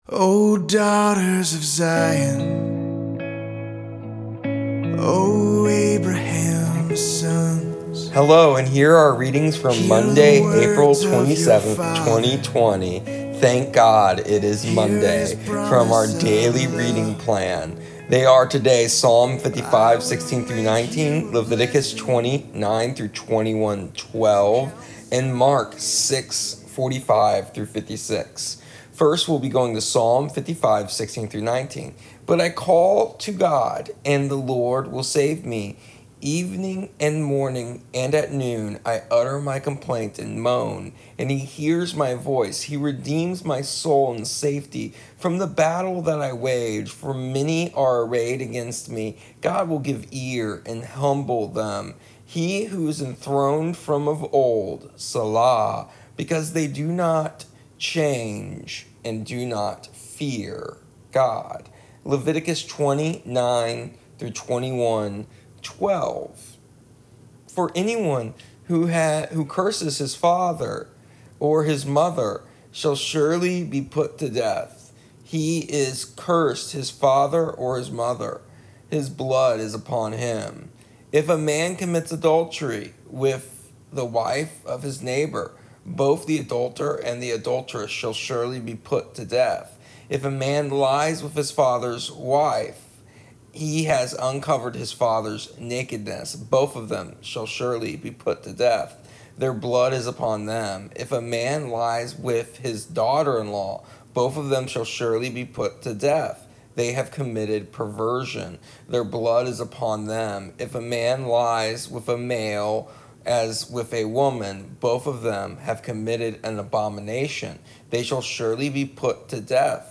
Here is the audio version of our daily readings from our daily reading plan Seek Him as we continue to seek after Him as a family of families.